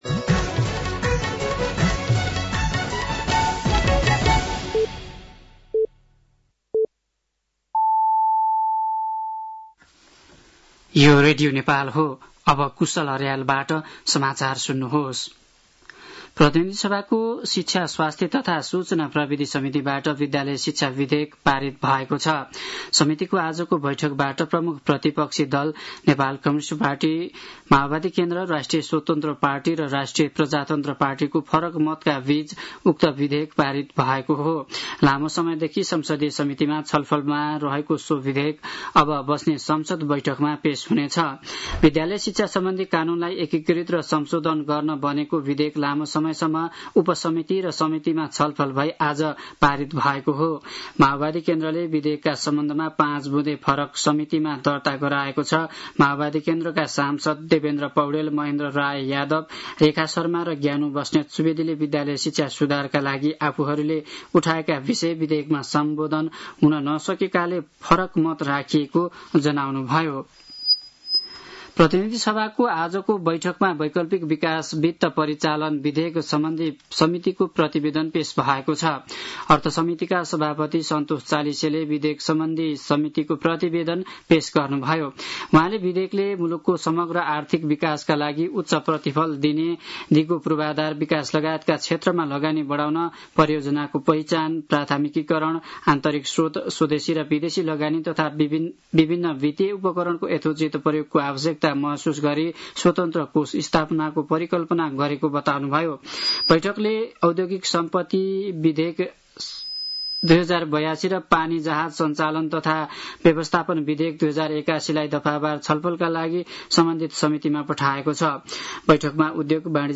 साँझ ५ बजेको नेपाली समाचार : ५ भदौ , २०८२
5-pm-news-5-5.mp3